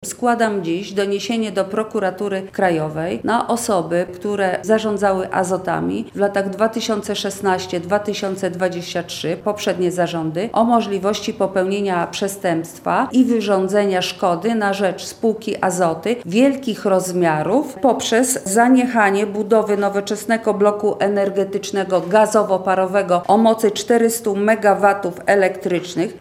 – Puławskie Azoty decyzjami poprzednich zarządów zostały pozbawione udziału w rynku mocy – mówi europosłanka Marta Wcisło.